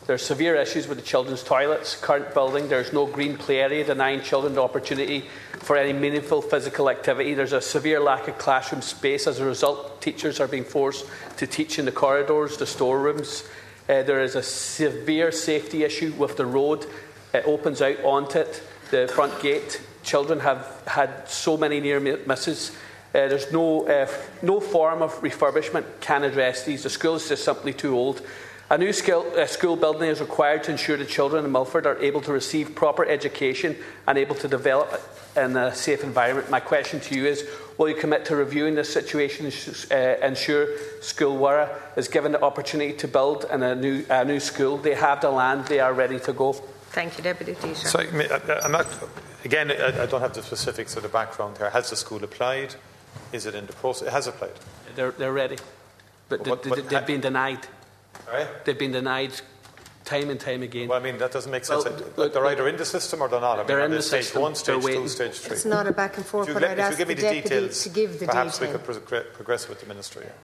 Speaking to Taoiseach Micheal Martin in the Dail, Deputy Charles Ward from the 100% Redress Party says the school has been denied permission to build despite having land to do so.